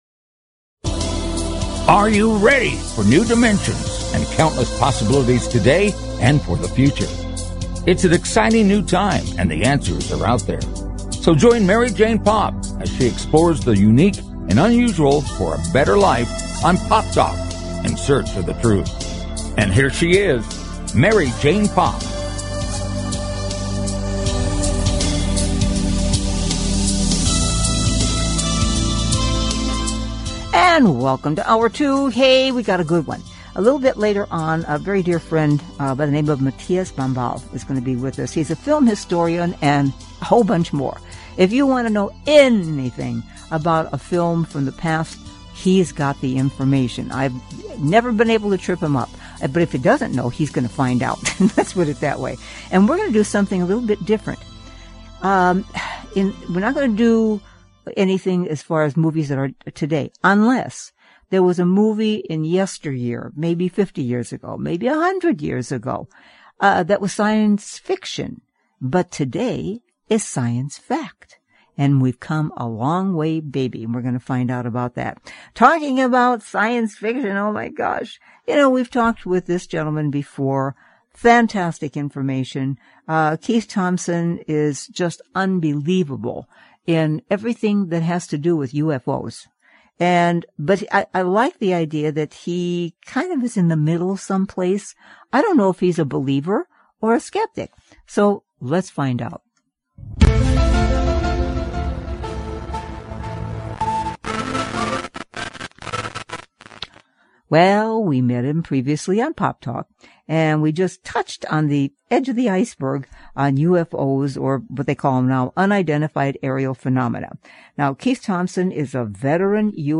Talk Show Episode, Audio Podcast
A fast-paced Magazine-style Show dedicated to keeping you on the cutting edge of today's hot button issues. The show is high energy, upbeat and entertaining.